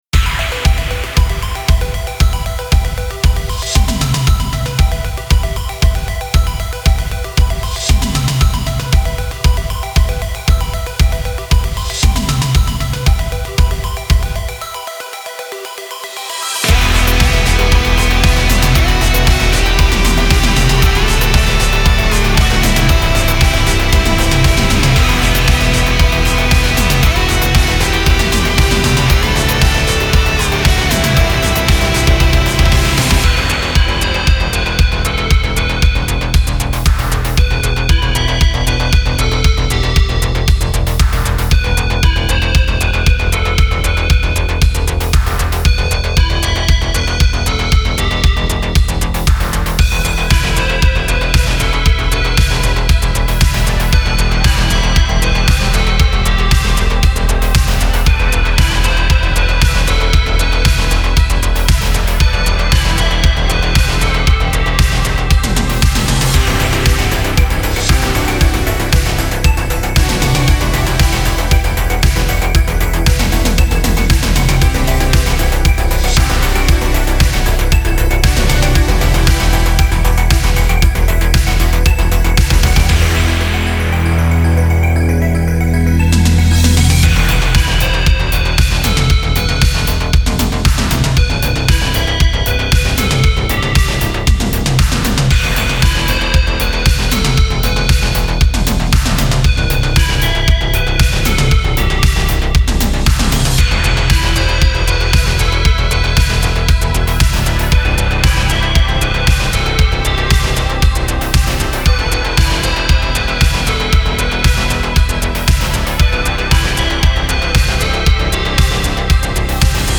Genre: Synthpop Synthwave.